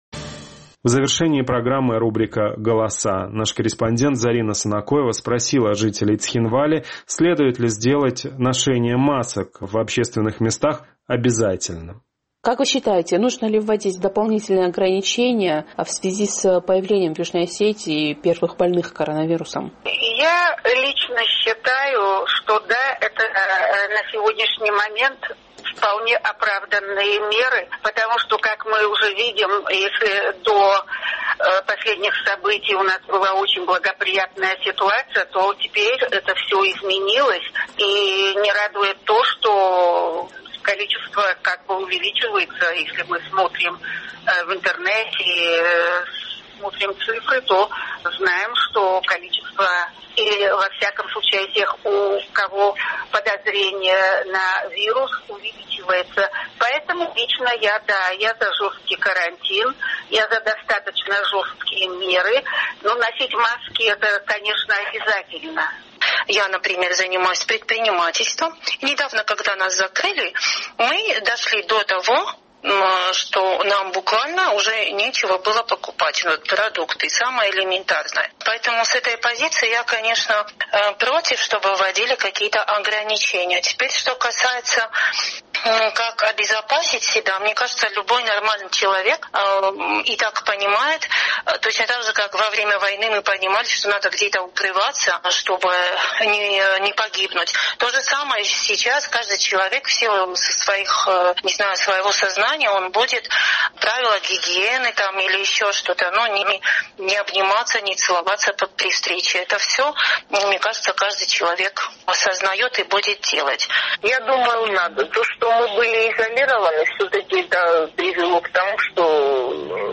Число зараженных коронавирусом в Южной Осетии за последние 24 часа выросло с 3 до 11. Мы спросили у цхинвальцев, следует ли ужесточить карантин и обязать людей носить маски в общественных местах?